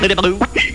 Giggledygulp Sound Effect
giggledygulp.mp3